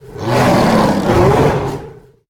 语音
LOE_051_JungleMoonkin_Play.ogg